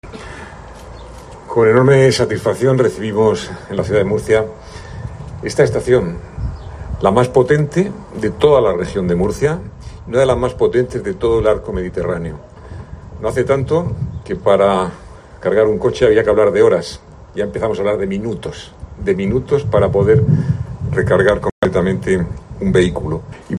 José Ballesta, alcalde de Murcia